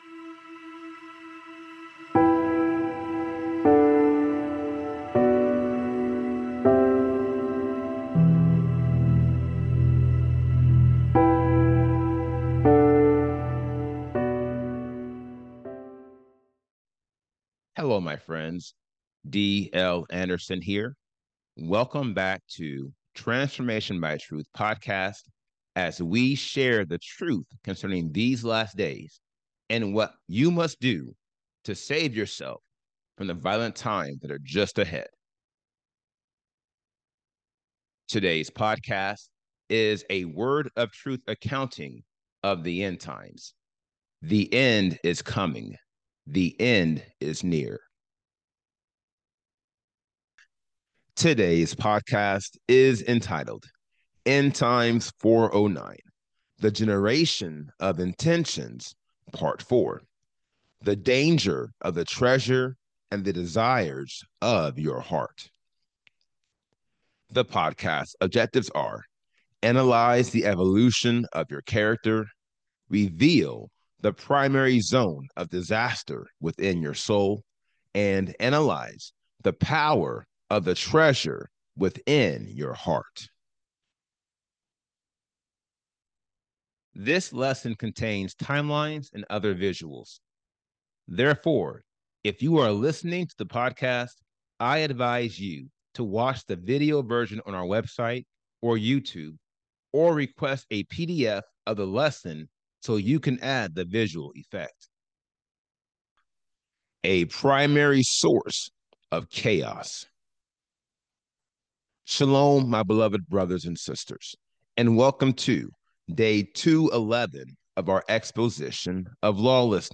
This podcast is a 400-level lecture dedicated to analyzing the 80 degrees of lawlessness and showing you how you can eliminate each one to obtain the Seal of Elohim. Its purpose is to analyze the evolution of your character, reveal the primary zone of disaster within your soul, and analyze the power of the treasure within your heart.